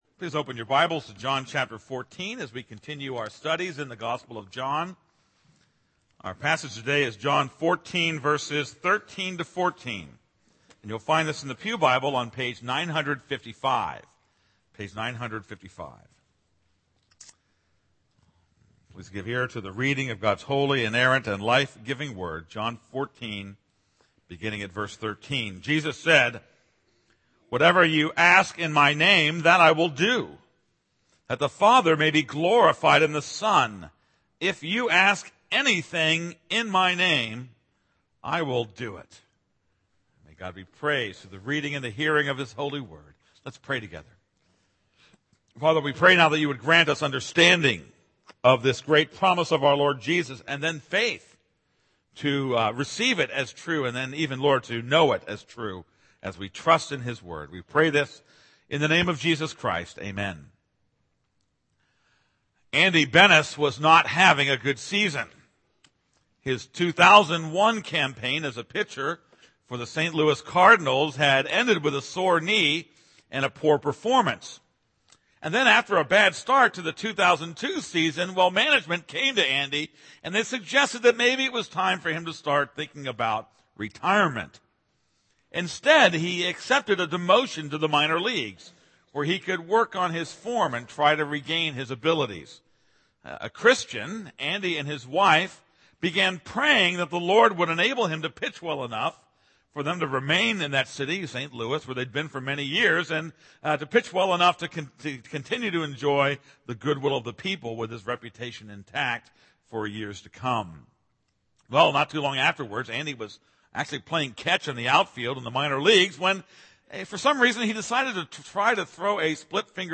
This is a sermon on John 14:13-14.